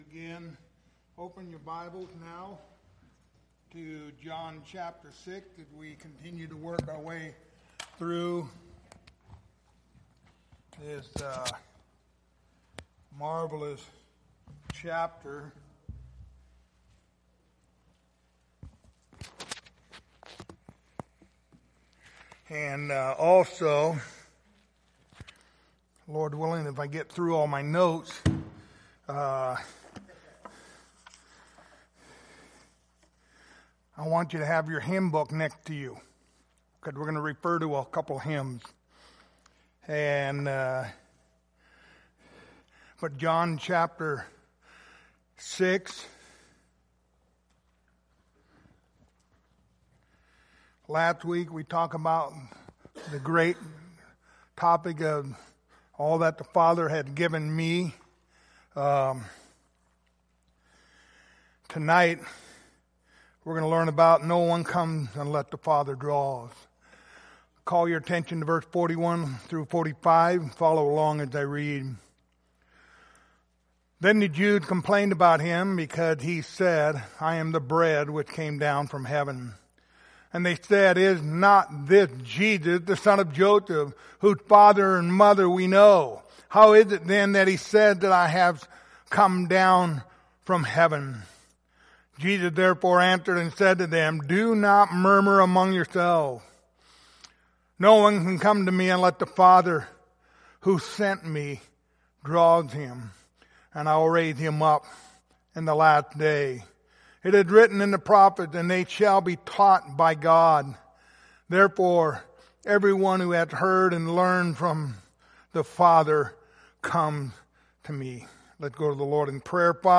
The Gospel of John Service Type: Wednesday Evening Topics